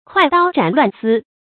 快刀斬亂絲 注音： ㄎㄨㄞˋ ㄉㄠ ㄓㄢˇ ㄌㄨㄢˋ ㄙㄧ 讀音讀法： 意思解釋： 比喻做事果斷，能采取堅決有效的措施，很快解決復雜的問題。